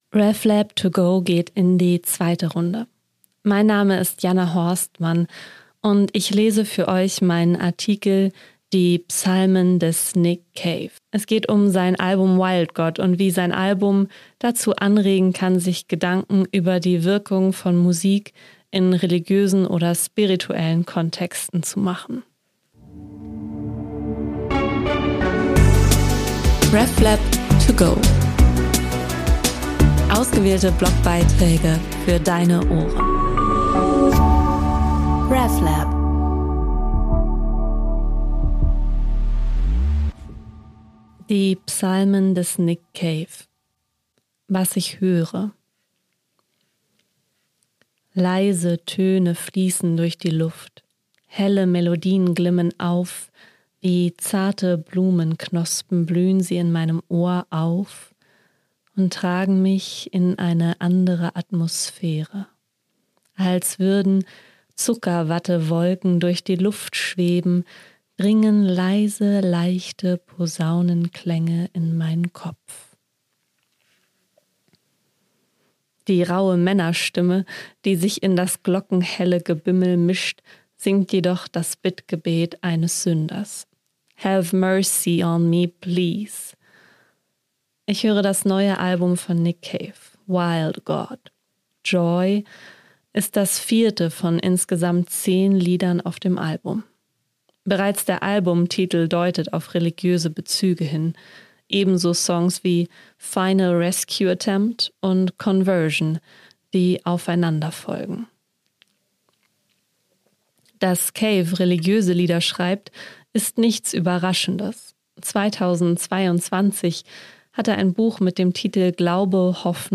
Bereits der Albumtitel deutet auf religiöse Bezüge hin, ebenso Songs wie «Final Rescue Attempt» und «Conversion», die aufeinander folgen. Geschrieben und vorgelesen